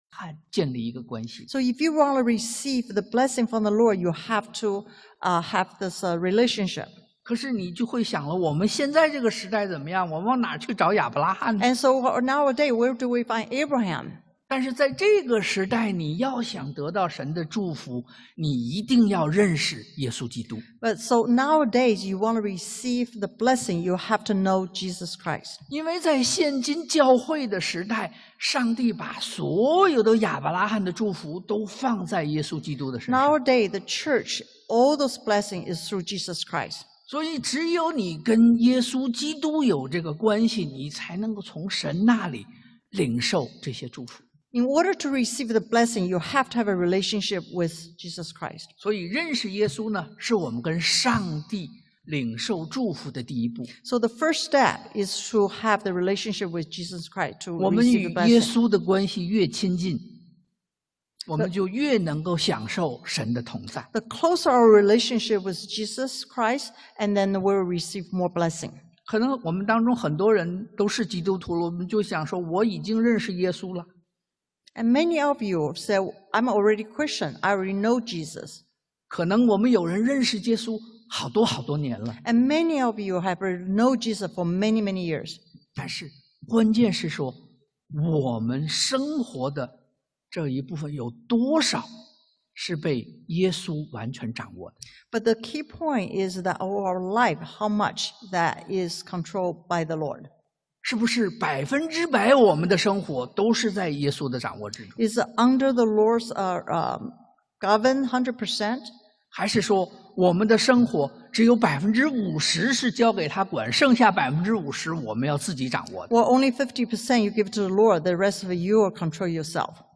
Passage: 創 Gen 13:1-18 Service Type: Sunday AM Do You Want God’s Blessing?